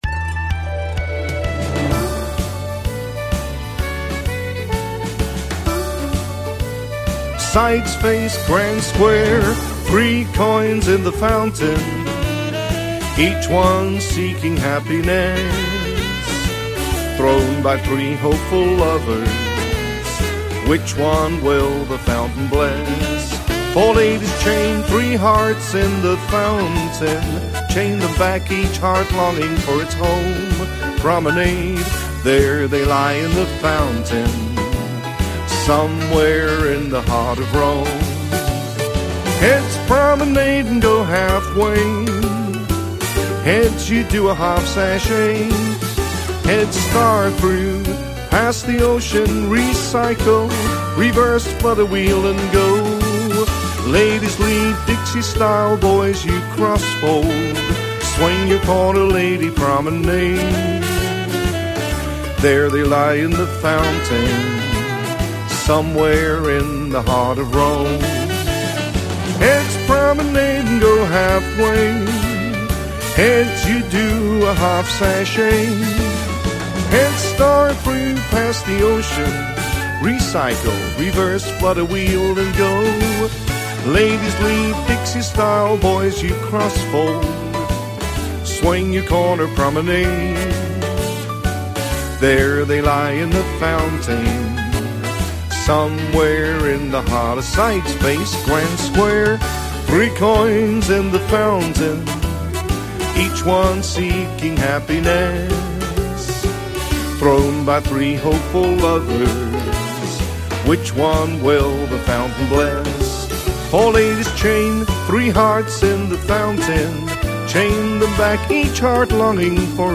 Singing Calls
Big Band